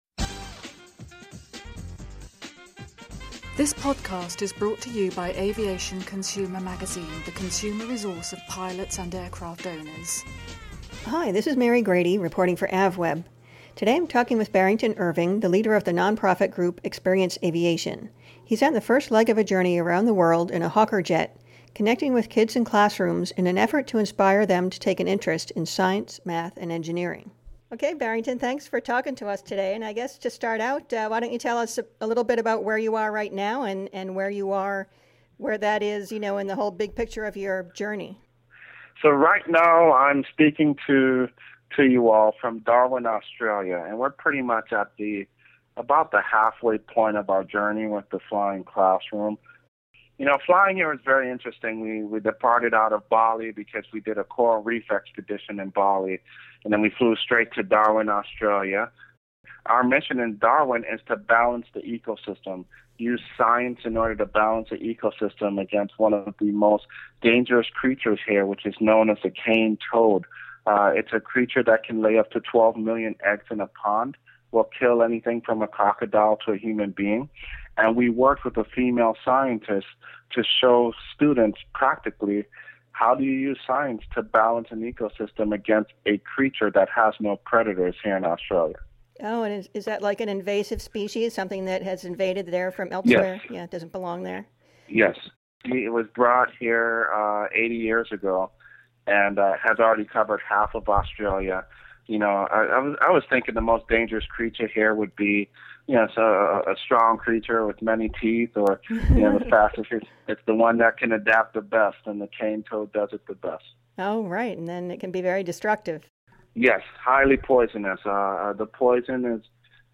during a stopover in Australia about the project, the jet, and the expeditions he’s taking part in along the way.